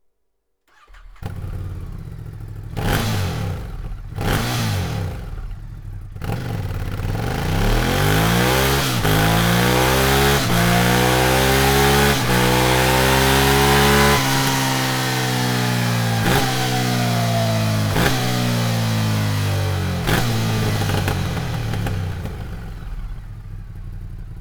Sound Serienauspuff